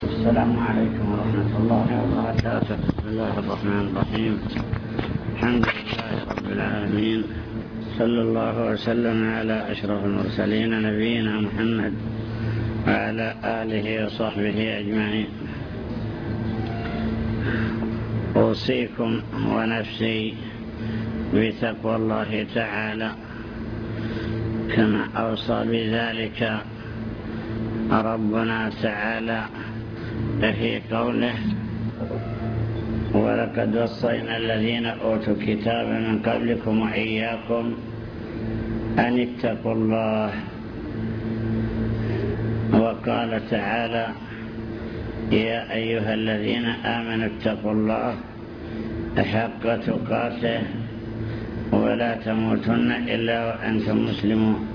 المكتبة الصوتية  تسجيلات - لقاءات  كلمة للمعلمين وطلاب التحفيظ وصايا من الشيخ